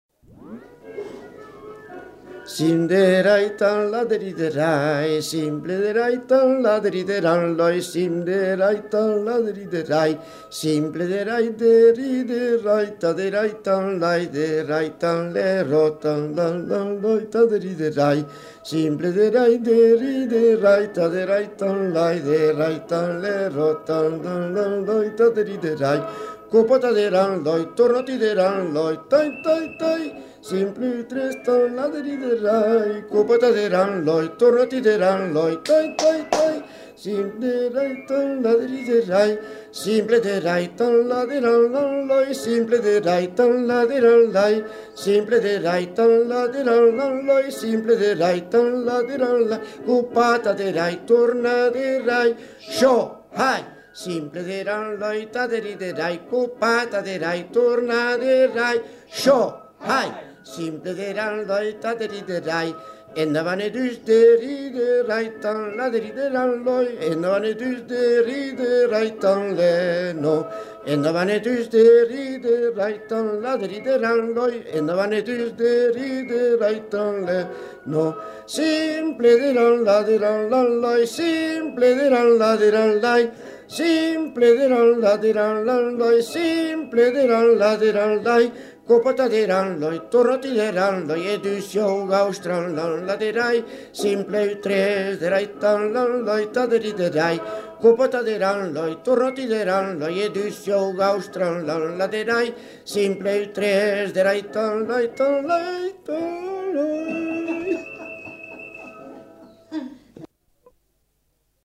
Aire culturelle : Béarn
Lieu : Bielle
Genre : chant
Effectif : 1
Type de voix : voix d'homme
Production du son : chanté